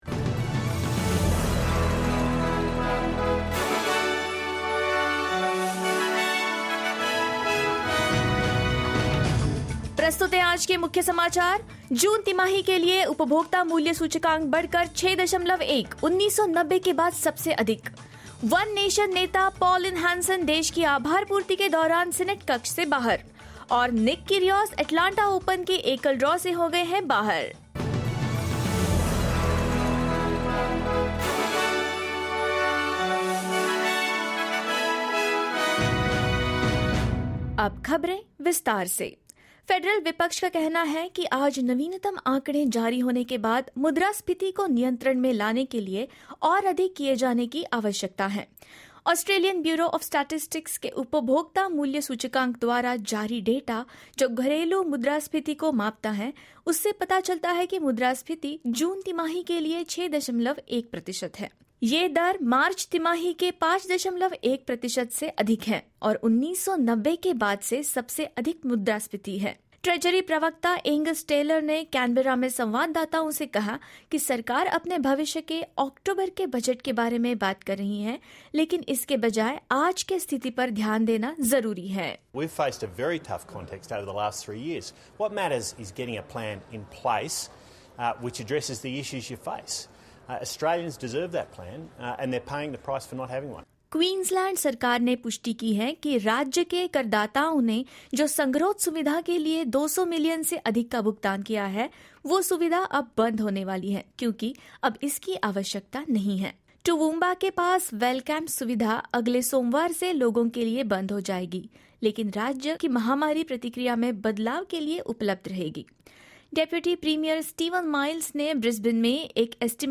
In this latest SBS Hindi bulletin: The latest consumer price index shows that inflation is at 6.1 percent for the June quarter; One Nation leader Pauline Hanson leaves Senate chamber during acknowledgement of country; Tennis star Nick Kyrgios drops out of the singles draw at the Atlanta Open and more.